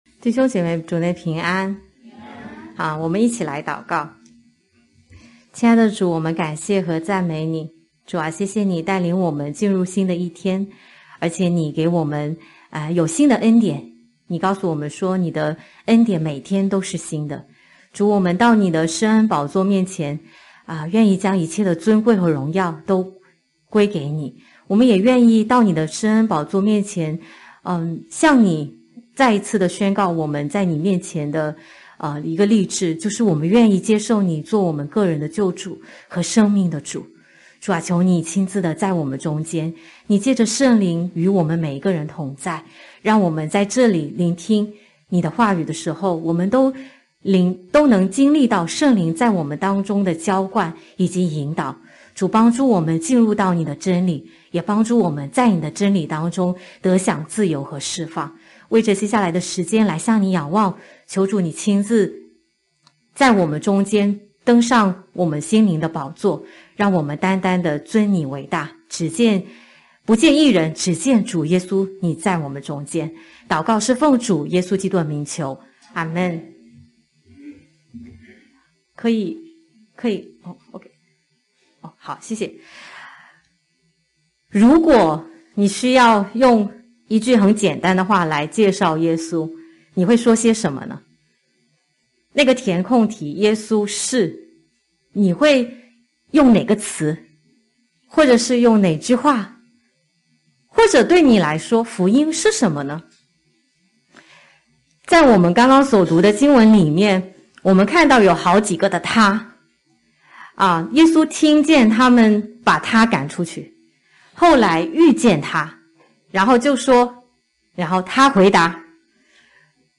国语堂主日崇拜-《未完，待续》-《约翰福音9-35-38節》.mp3